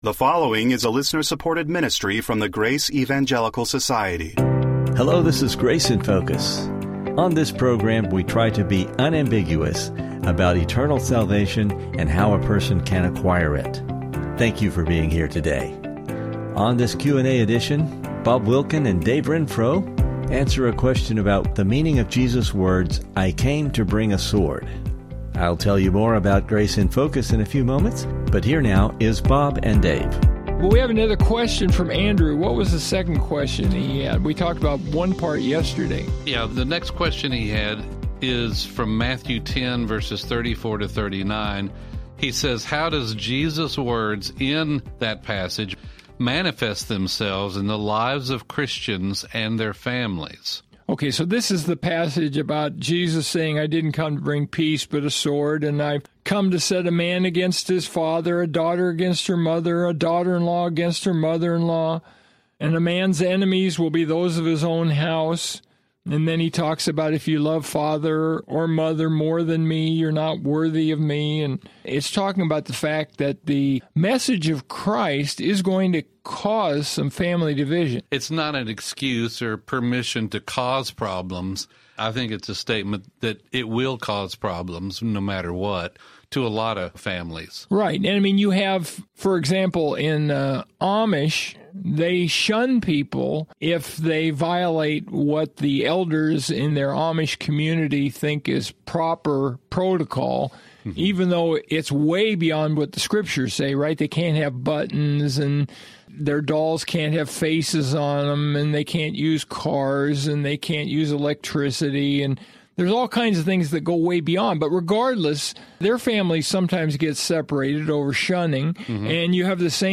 First, the guys will discuss Matt 10:34-39. We will hear about the Lord’s statement that He “came to bring a sword” and the application for us today.